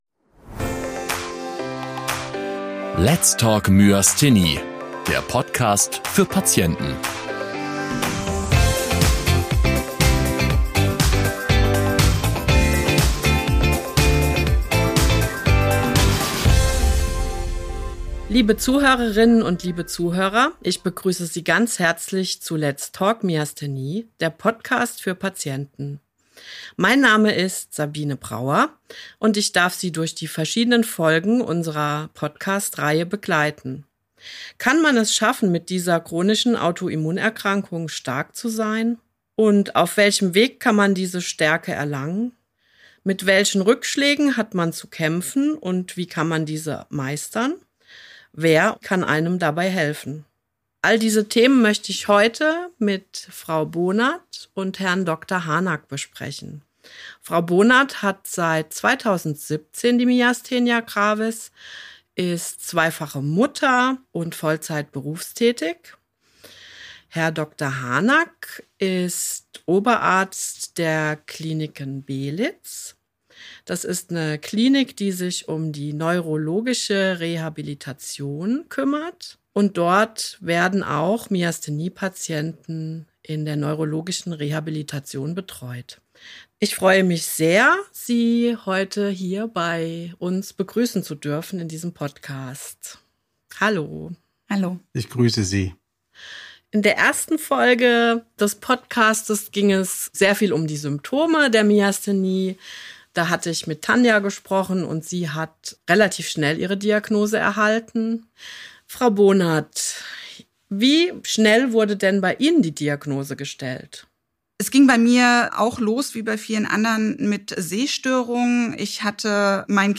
Diese und andere Fragen begleiten Myasthenie-Patienten und werden in der Diskussionsrunde dieser Folge ganz genau untersucht.